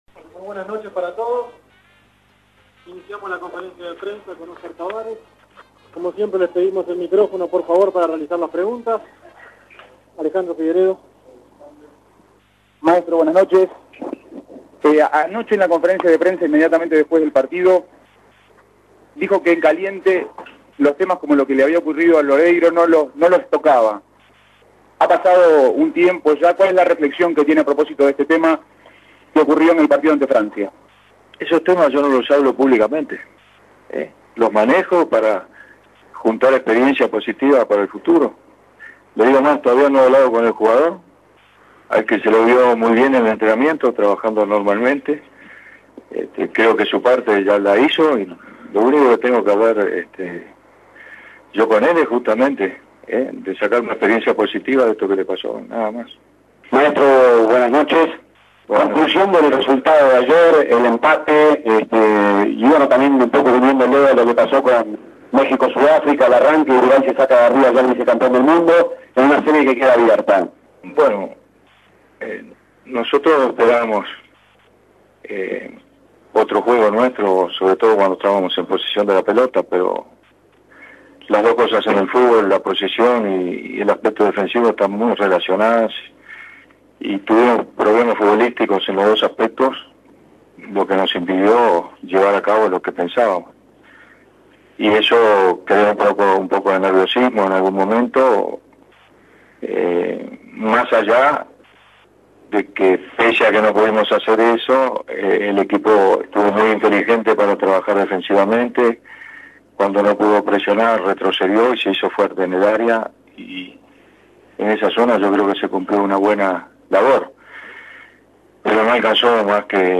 Escuche la conferencia de prensa de Oscar Washington Tabarez luego del empate ante Francia.